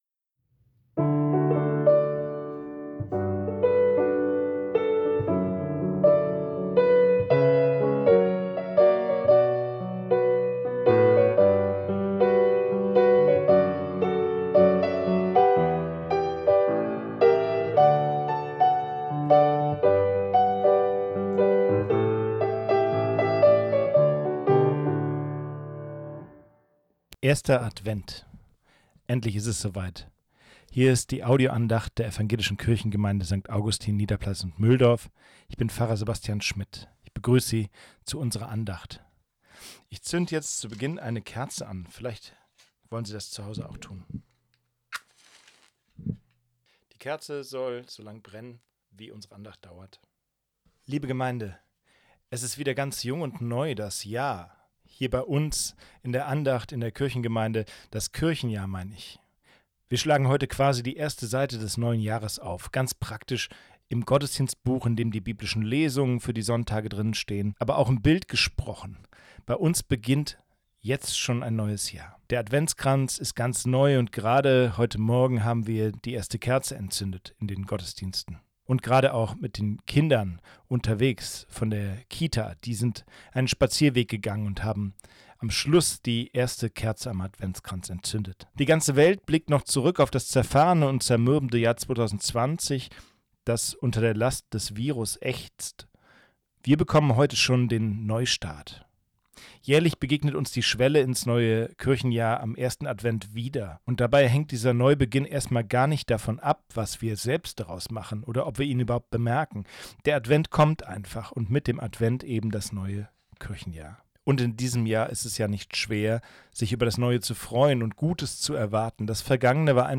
Radio Bonhoeffer – Der beste Mix aus Bibel, Songs und Menschen. Zum dritten Mal begrüßen wir Sie zu Radio-Bonhoeffer: Podcast & Gottesdienst und schlagen einen Bogen zwischen Seenotrettung im Mittelmeer und Weihnachten.
Categories: Allgemein | Tags: Abendgottesdienst, Advent, Audio, Gottesdienst, Podcast, Podcast-Gottesdienst, Radio Bonhoeffer, Risiko, Seenotrettung, Video, Weihnachten | Permalink.